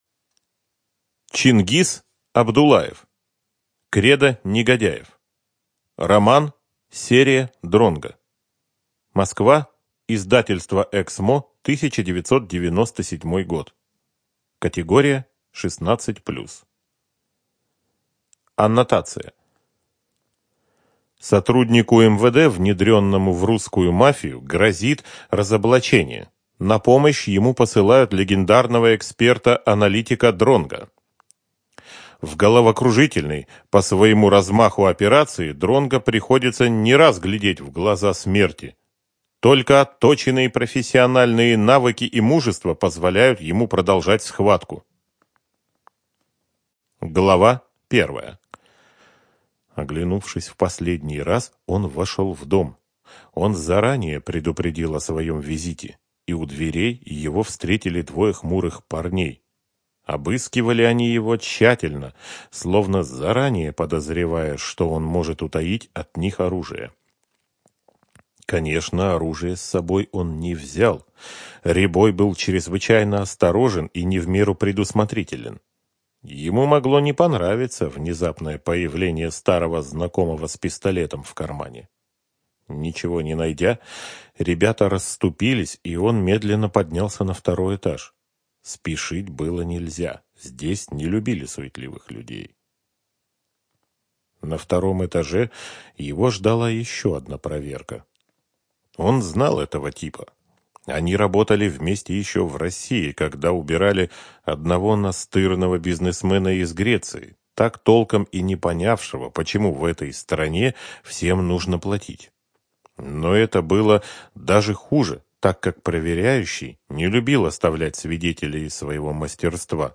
ЖанрДетективы и триллеры, Боевики
Студия звукозаписиЛогосвос